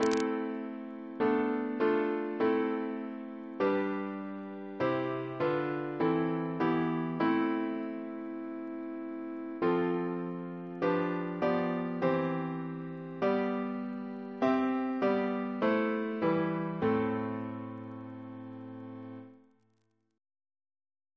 Double chant in A minor Composer: Thomas Tertius Noble (1867-1953) Reference psalters: H1982: S38